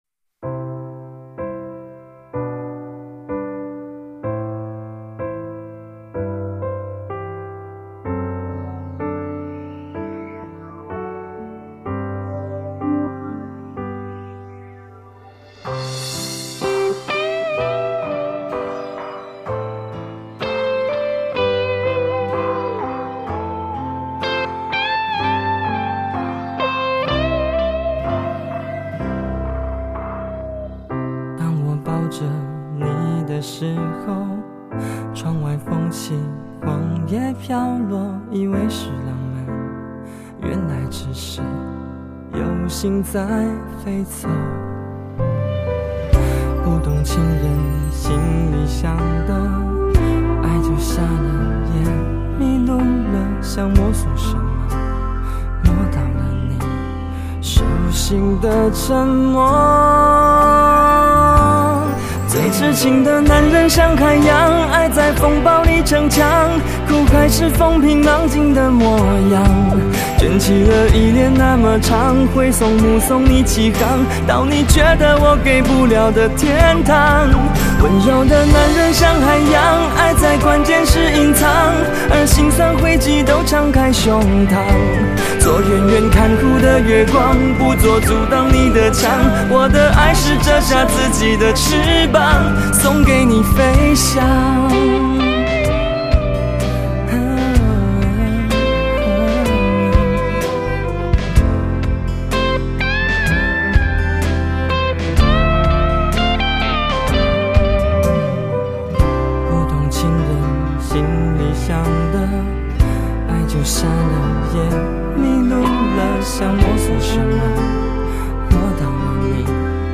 成人抒情歌曲